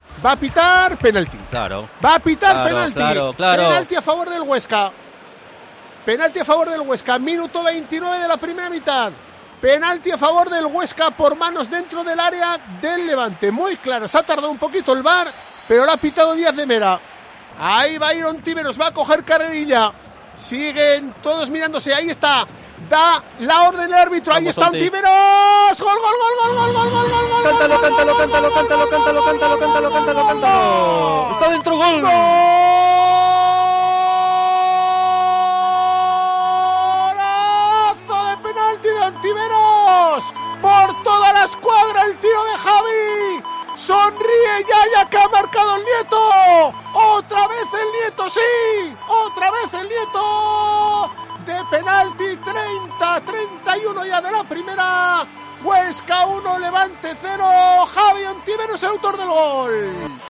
Narración Gol de Ontiveros / SD Huesca 1-0 Levante